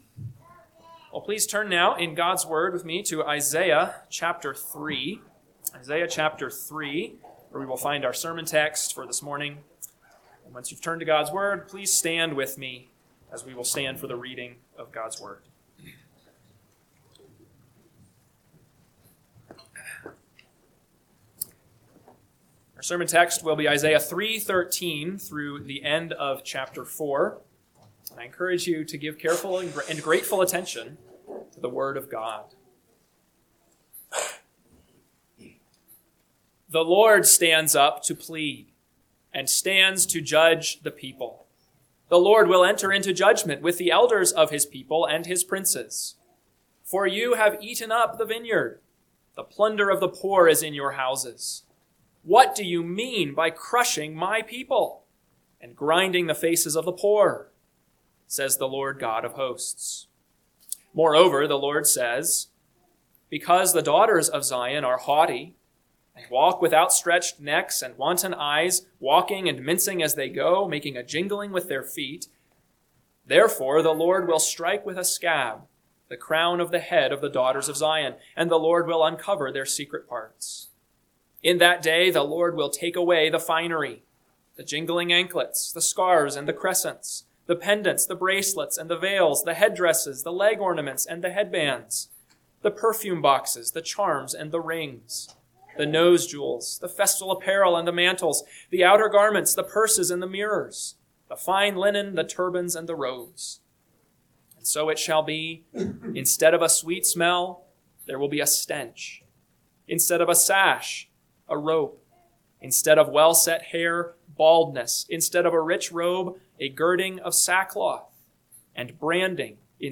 AM Sermon – 11/9/2025 – Isaiah 3:13-4:6 – Northwoods Sermons